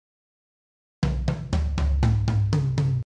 タム（タムタム）
通常、音の高さが異なる複数のタムを並べます。
toms.mp3